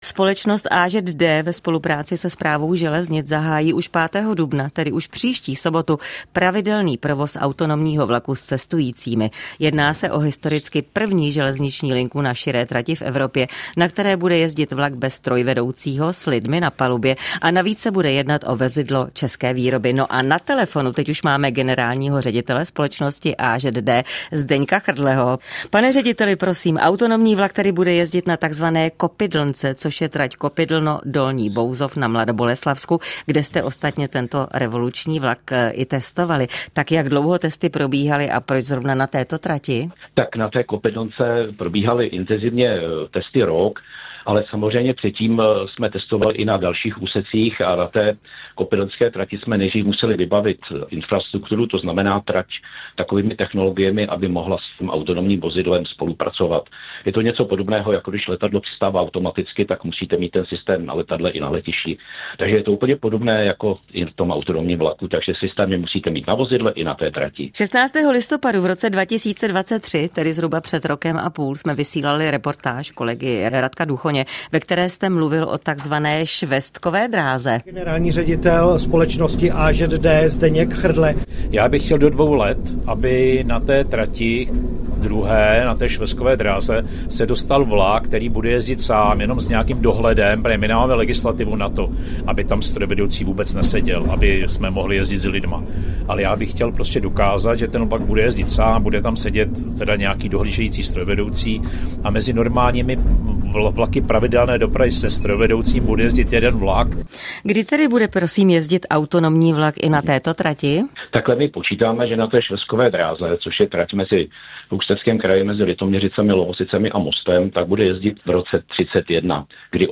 Zprávy Českého rozhlasu Střední Čechy: Další bezemisní zdroj připravují v mělnické elektrárně. Podle energetiků ho nikdo v Evropě nepoužívá - 29.03.2025